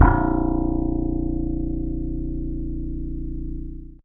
55v-bse04-a#1.aif